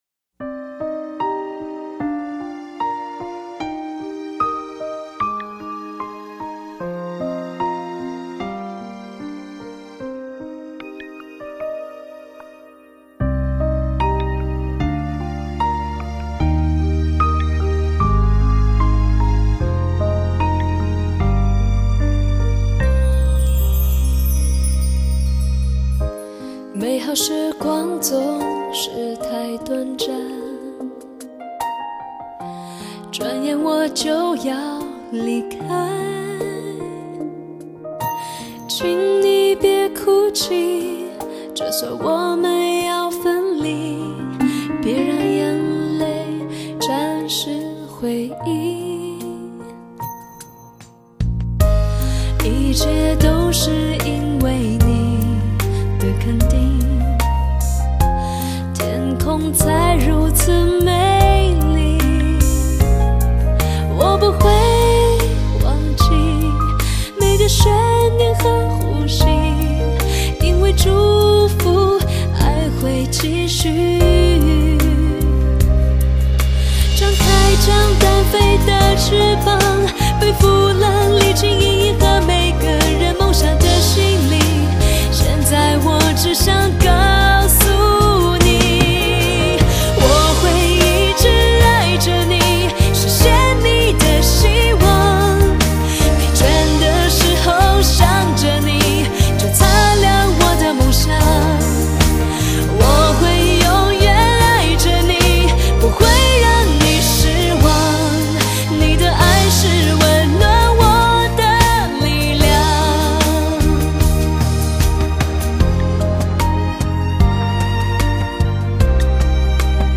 高能量的摇扶、悲伤的抒情